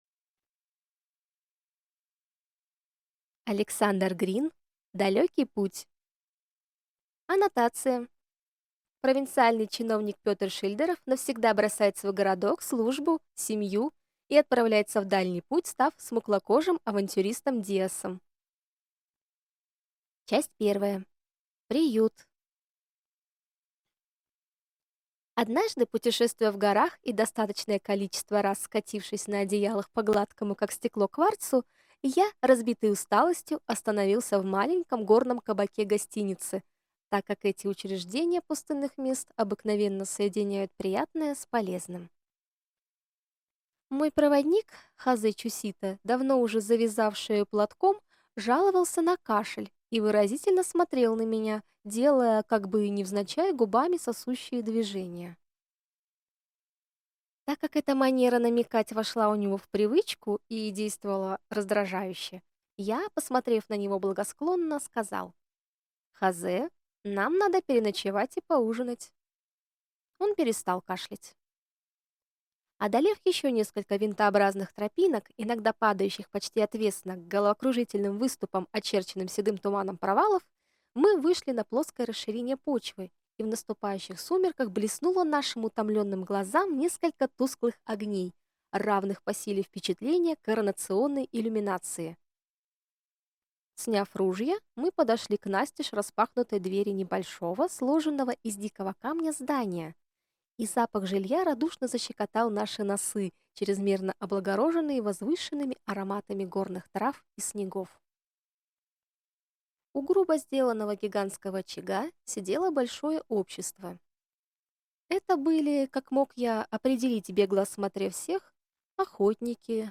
Aудиокнига Далекий путь